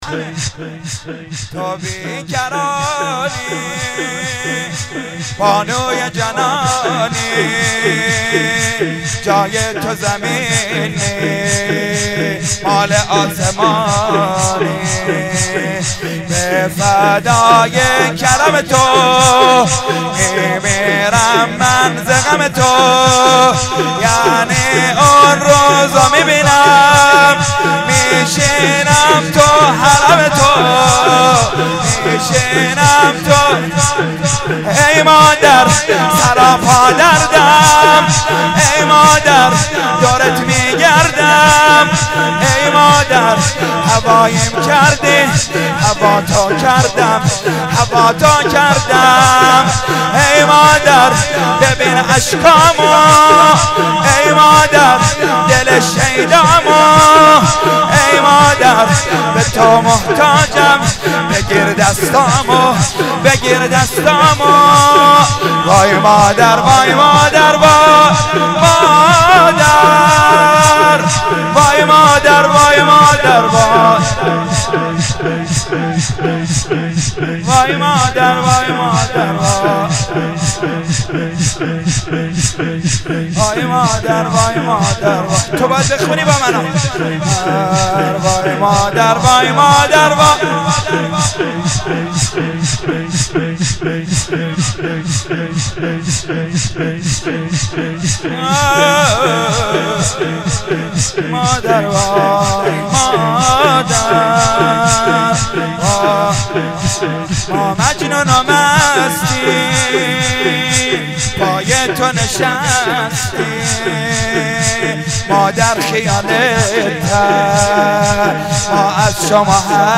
شور: